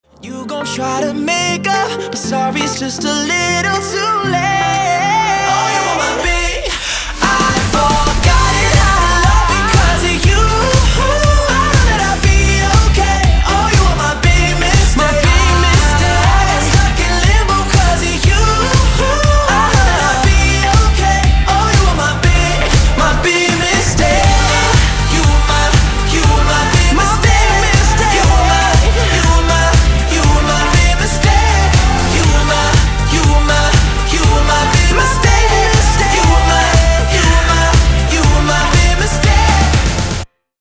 поп
мужской вокал
громкие
dance